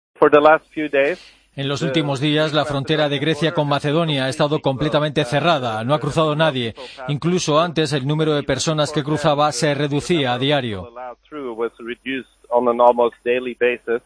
habla con la Cadena COPE desde el campo de refugiados de Idomeni, en la frontera entre ambos países.